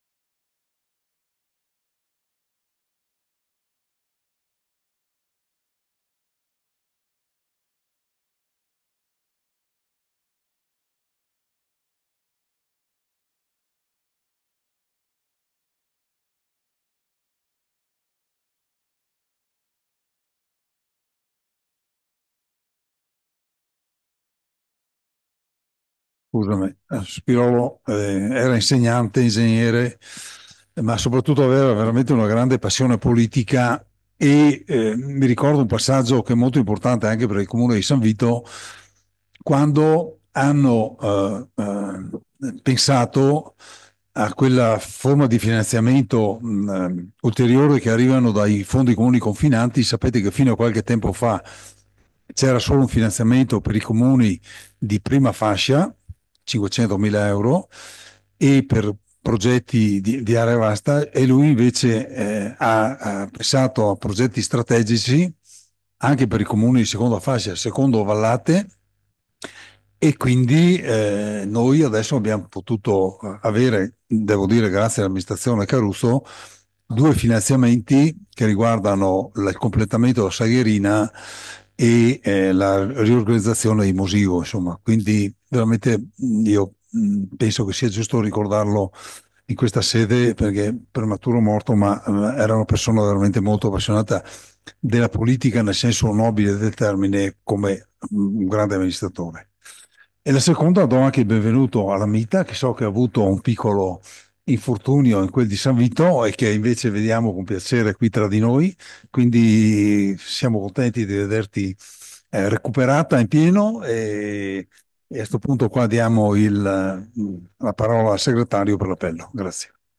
Registrazione audio Consiglio Comunale